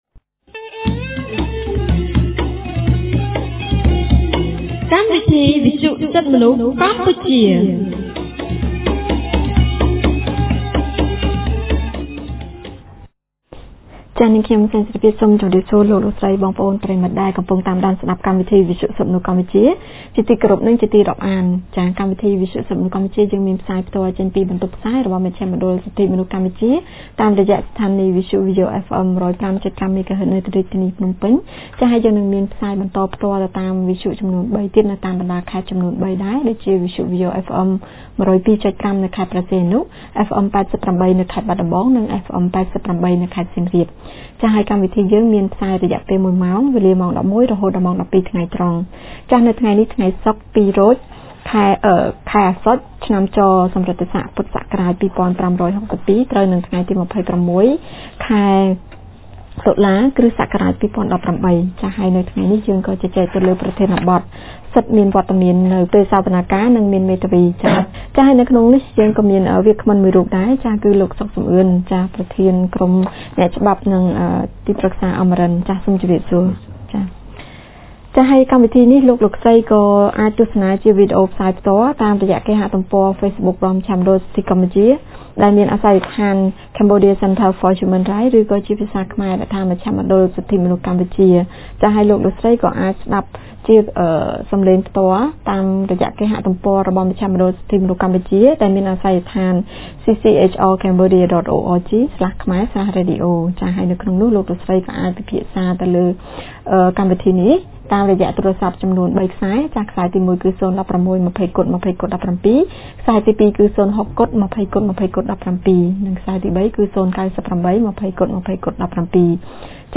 On 26 October 2018, CCHR’s Fair Trial Rights Project (FTRP) held a radio program with a topic on Right to be present at trial and to legal representation.